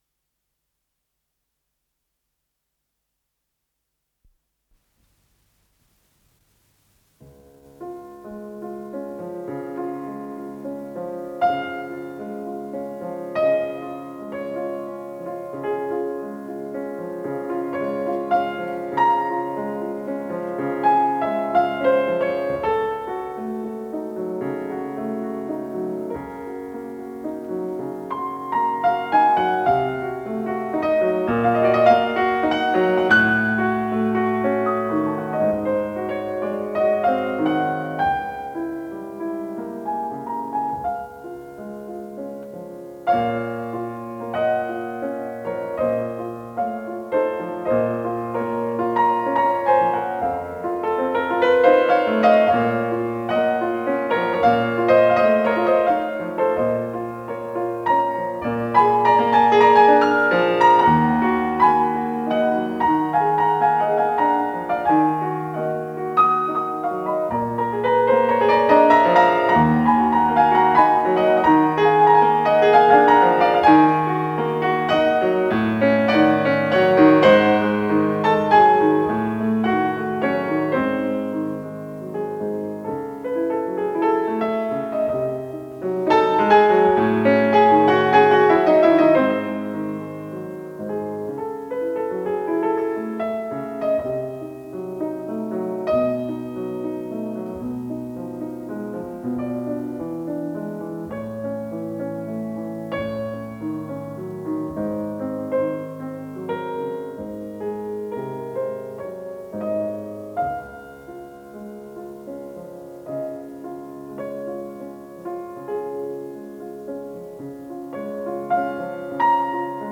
с профессиональной магнитной ленты
фортепиано
ВариантДубль моно